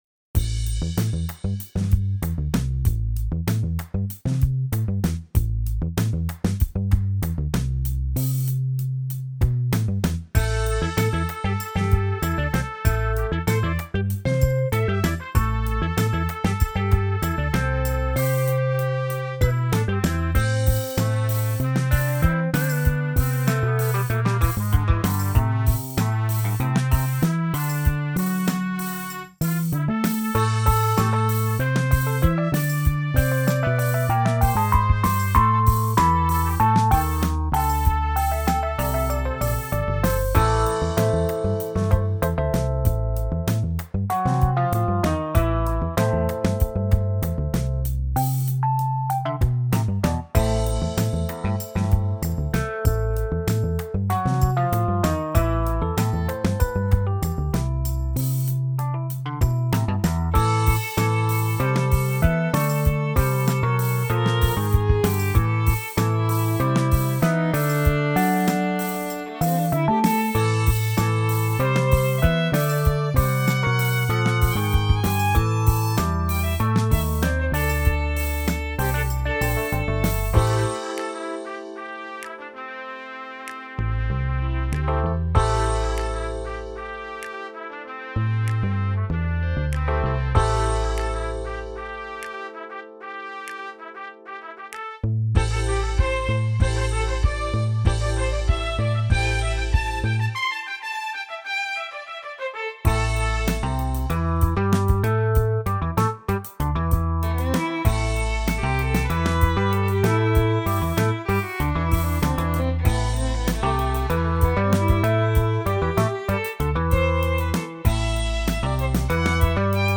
Secret Agent - Spy theme.